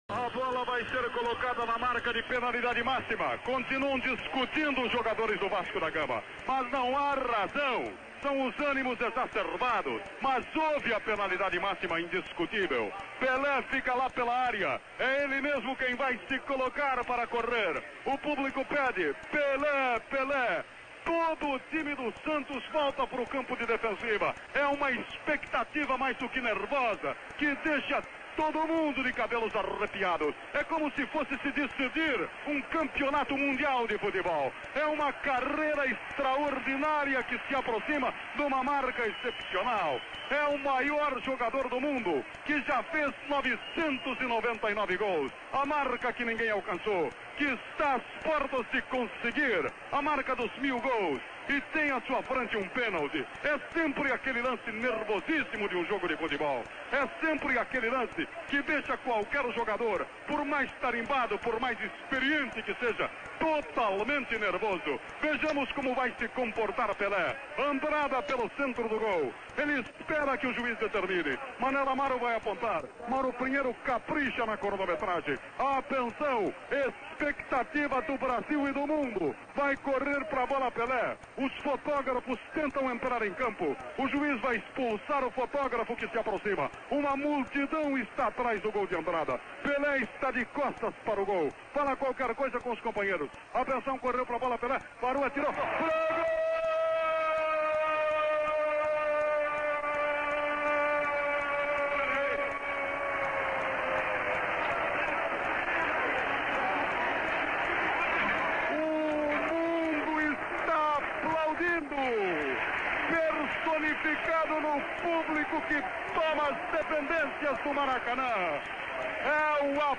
O jogo: Vasco da Gama x Santos.